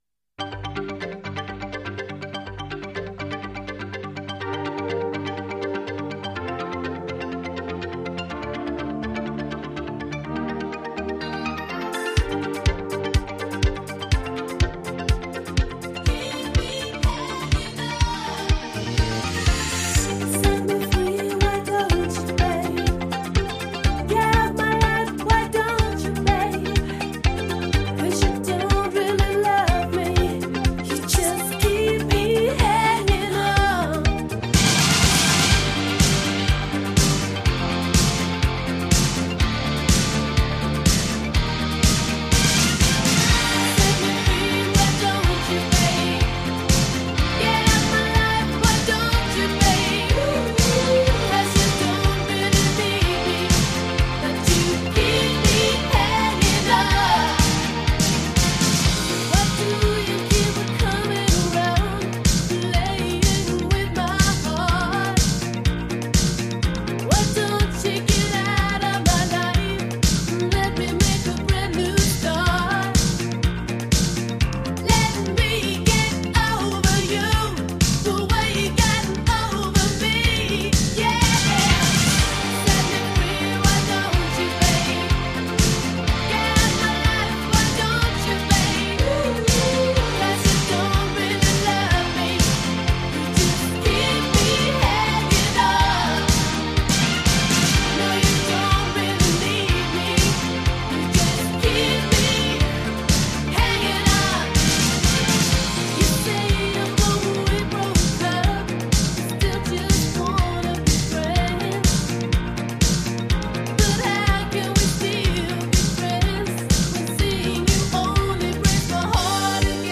на электрогитаре